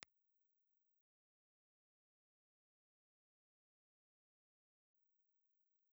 Piezoelectric Crystal
Cardioid
Impulse Response file of the Geloso 1100 Piezoelectric microphone.
Geloso_1100_Crystal_IR.wav